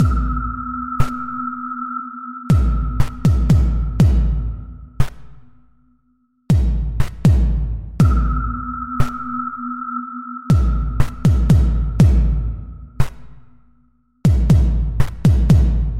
暗鼓
Tag: 60 bpm Ambient Loops Drum Loops 1.35 MB wav Key : Unknown